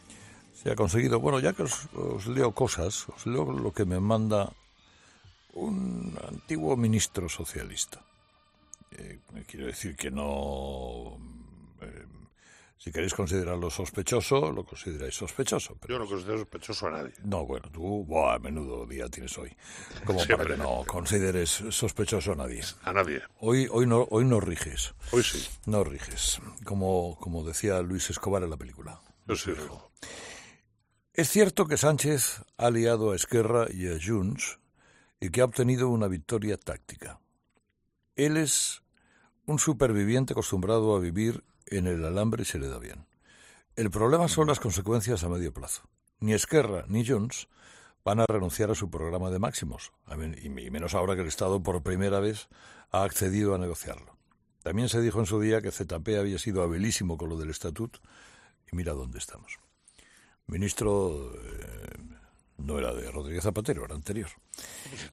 Carlos Herrera ha interrumpido la tertulia política de cada día en 'Herrera en COPE' para leer un mensaje que había recibido de un exministro del PSOE: “Os leo lo que me manda un antiguo ministro socialista. Si queréis considerarlo sospechoso, lo consideráis sospechoso.”